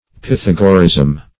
Search Result for " pythagorism" : The Collaborative International Dictionary of English v.0.48: Pythagorism \Py*thag"o*rism\, n. [Gr.